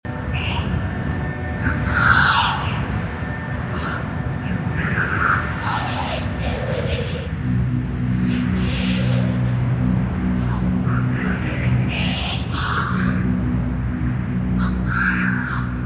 ヴォコーダによるメッセージ（ホワイト・ノイズをヴォコーダで変調）